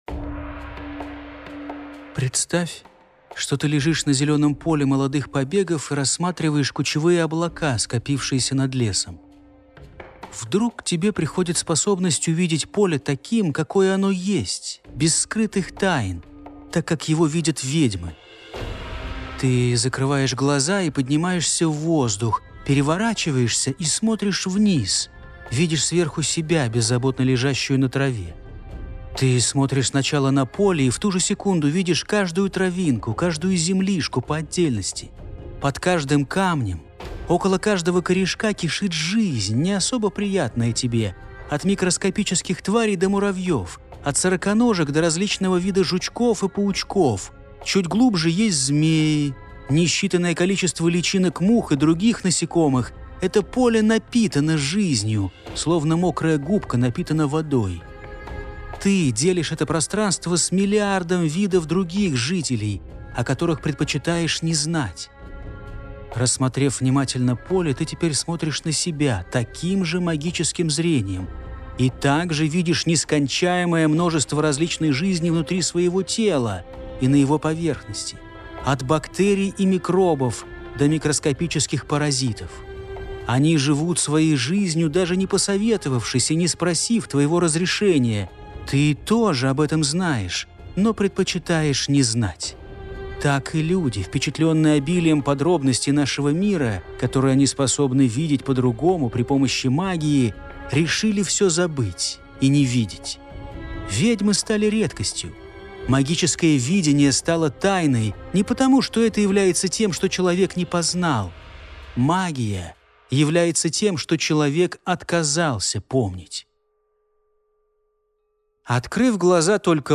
Аудиокнига Мемуары Ведьмы | Библиотека аудиокниг